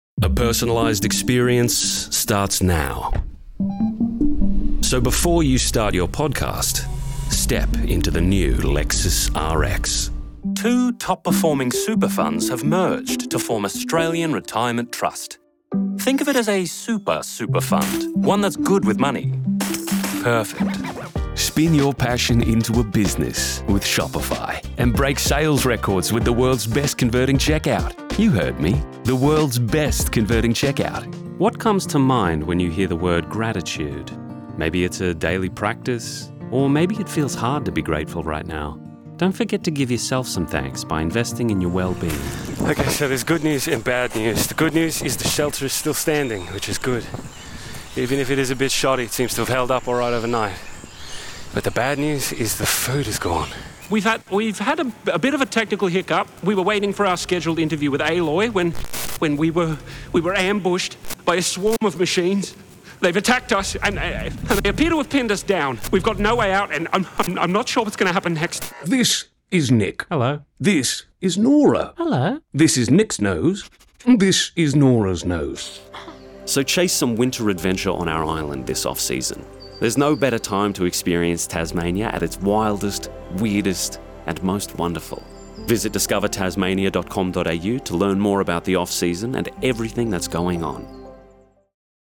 VO_Anglais_2025